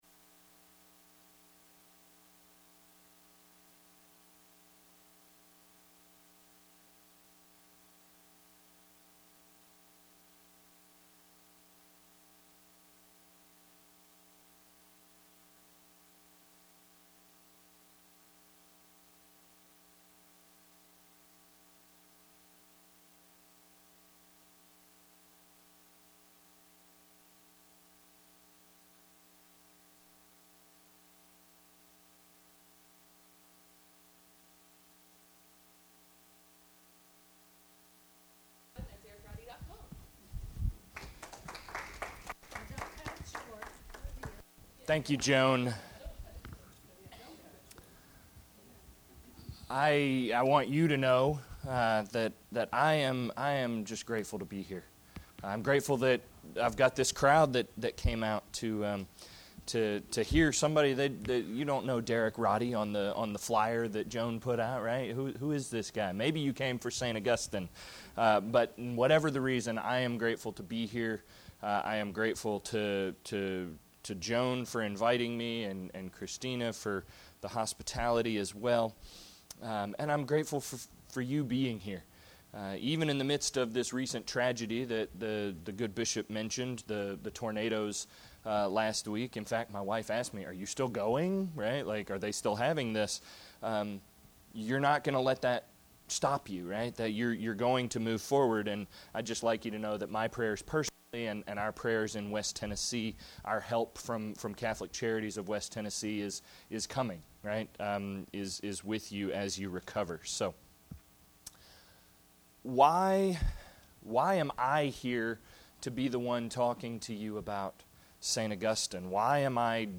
This presentation took place at the Diocese of Nashville Catholic Pastoral Center on March 11, 2020, as part of their Lenten Lecture Series.
[Audio begins at ~40 seconds.] https